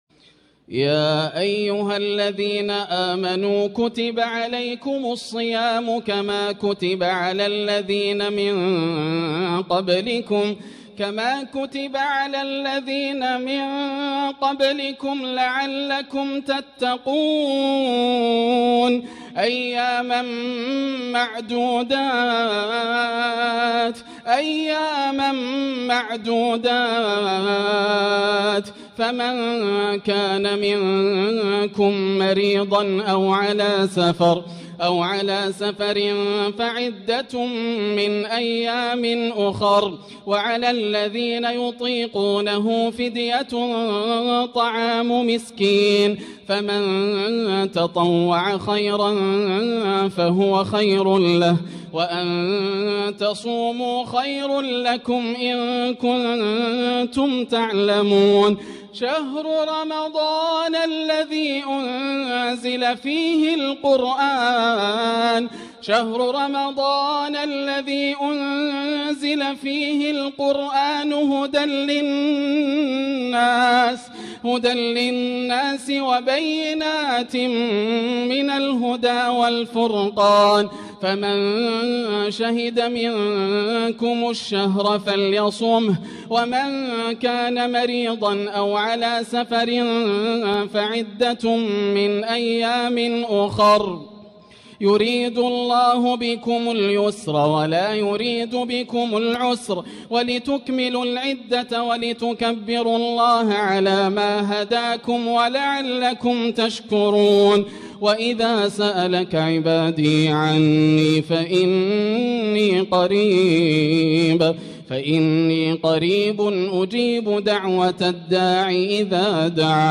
تلاوة مسترسلة بتنقلات خاشعة > الروائع > رمضان 1445هـ > التراويح - تلاوات ياسر الدوسري